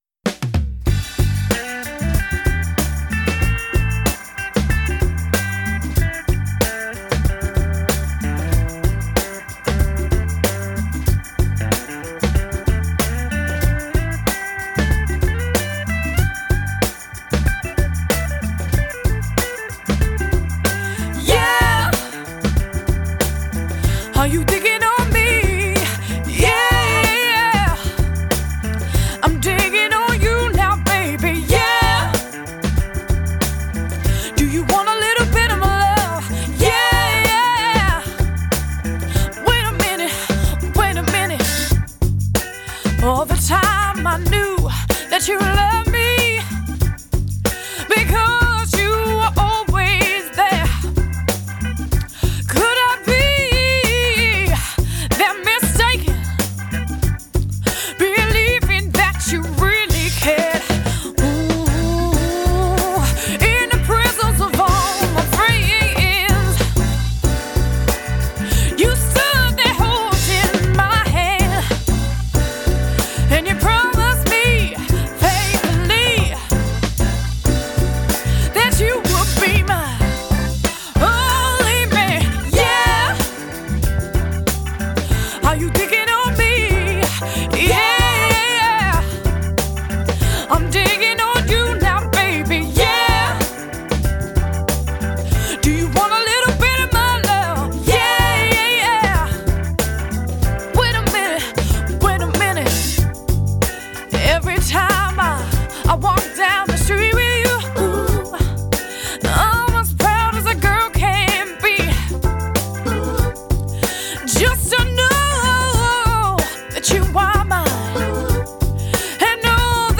Género: Groove.